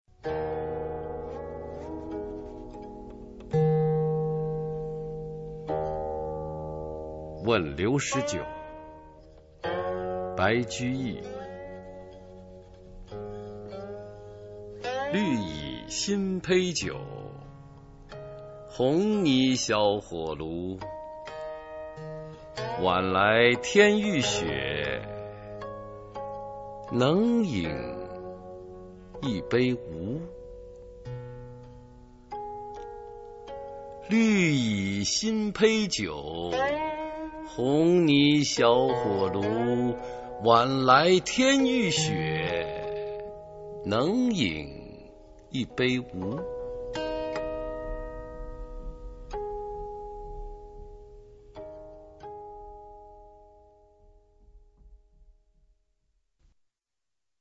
[隋唐诗词诵读]白居易-问刘十九 唐诗诵读